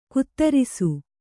♪ kuttarisu